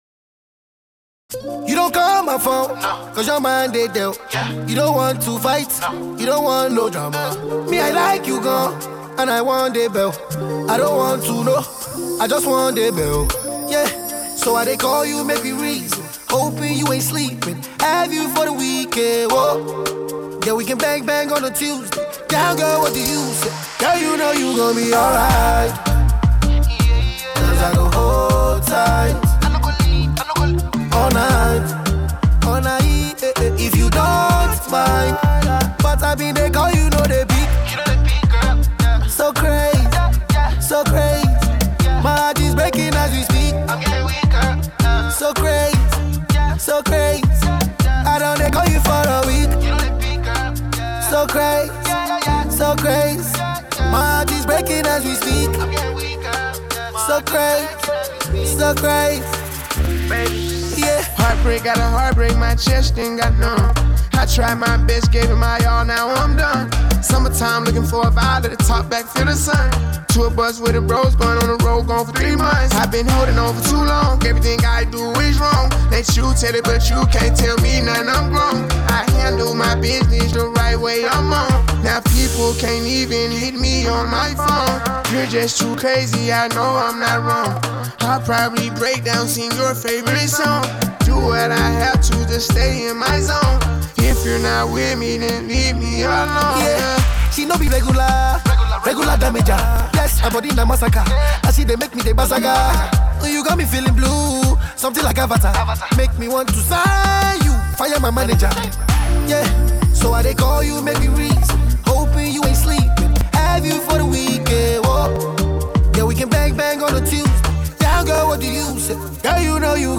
it’s a party rocker.